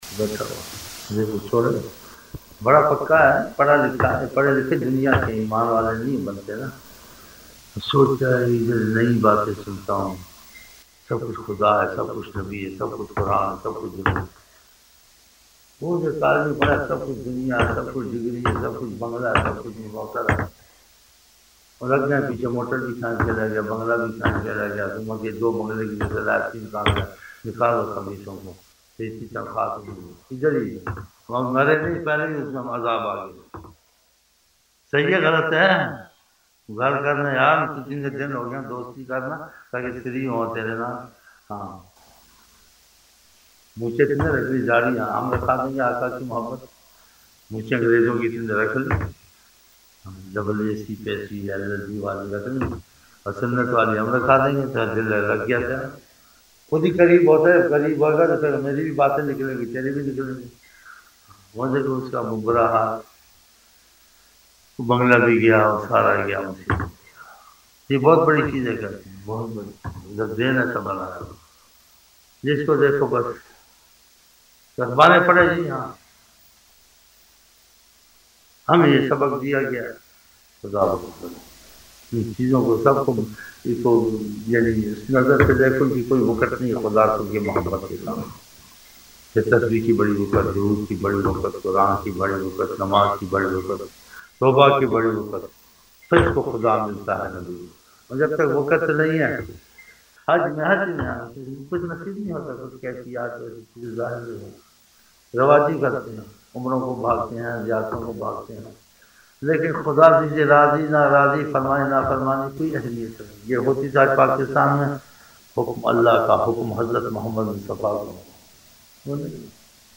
ظہر شروع کی محفل